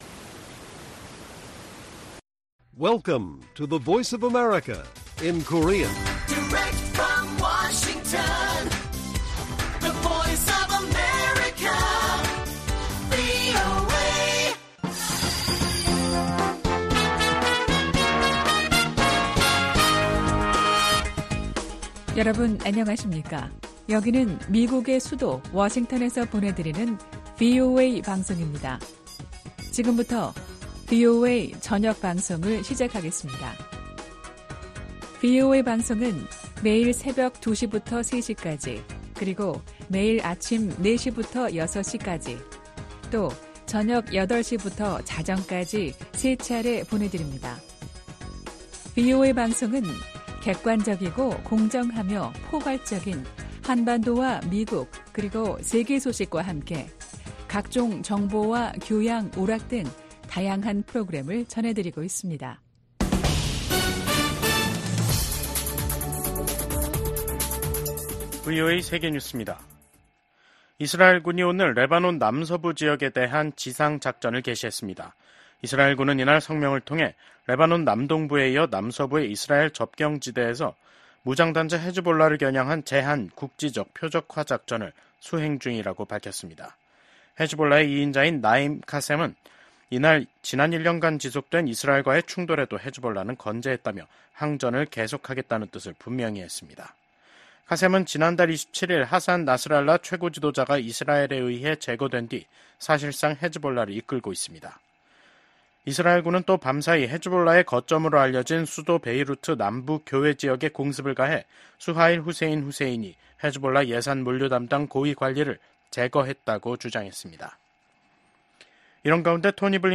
VOA 한국어 간판 뉴스 프로그램 '뉴스 투데이', 2024년 10월 8일 1부 방송입니다. 김정은 북한 국무위원장은 적들이 무력 사용을 기도하면 주저없이 핵무기를 사용할 것이라고 위협했습니다. 미국 정부가 북한 해킹조직 라자루스가 탈취한 가상 자산을 압류하기 위한 법적 조치에 돌입했습니다.